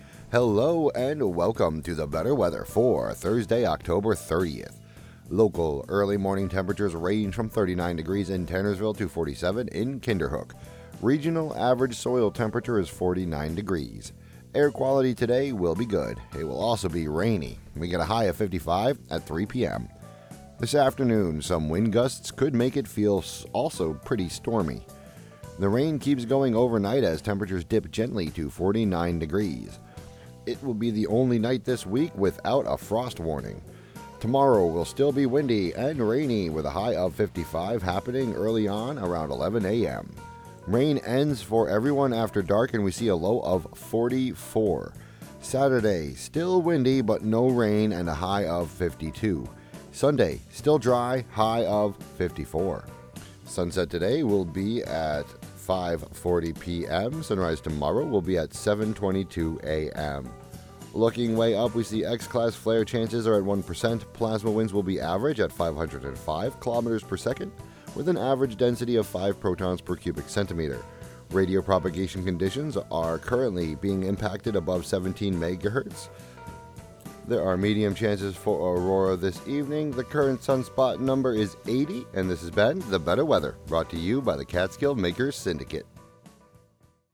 and more on WGXC 90.7-FM.